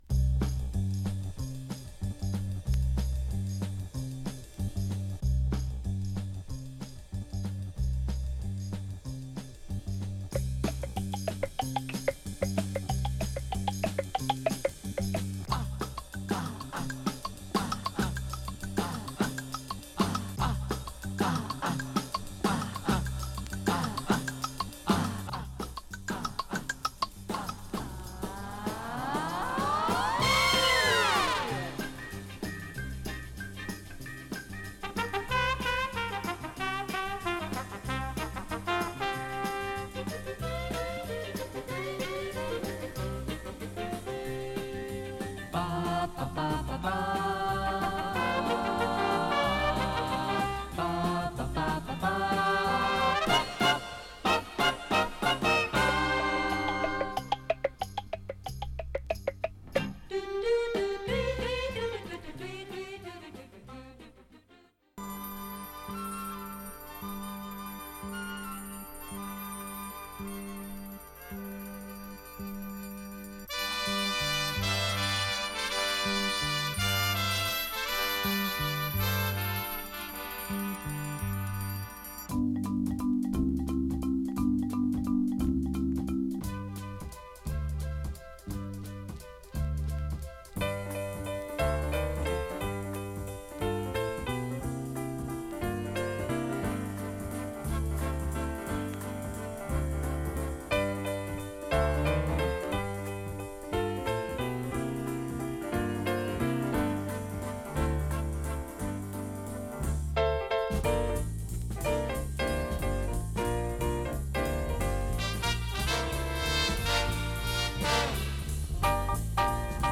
当時流行していたモーグ・シンセをビックバンドの融合によるプロジェクト。